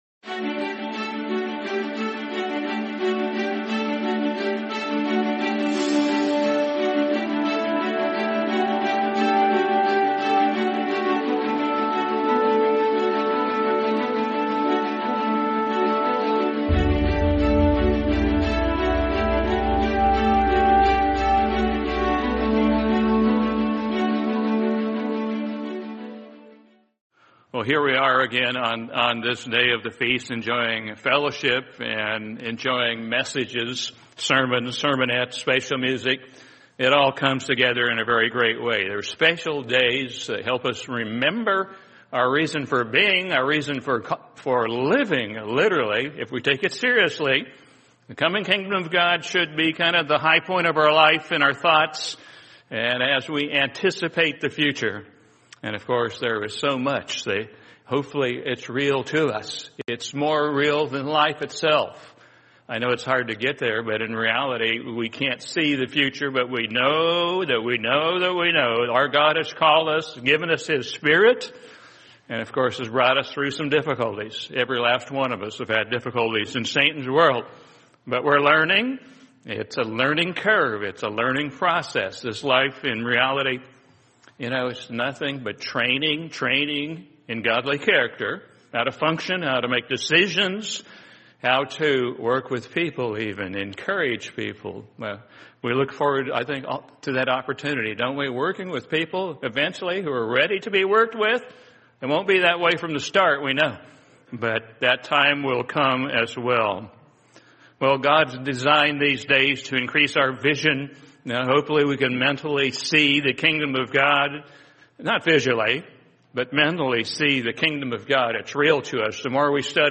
Feast of Tabernacles 2025: This Is the Way, Walk in It | Sermon | LCG Members